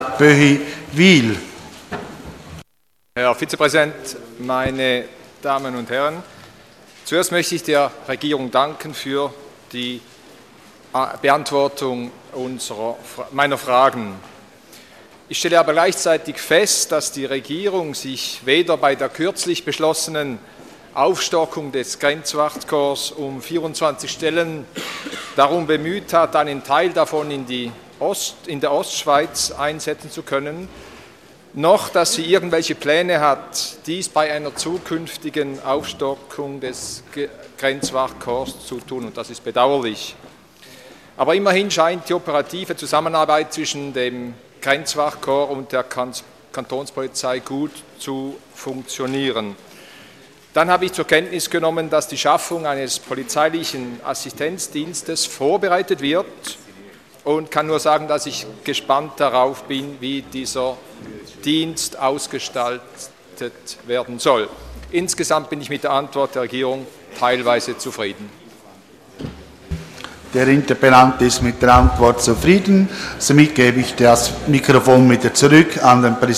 25.11.2013Wortmeldung
Session des Kantonsrates vom 25. und 26. November 2013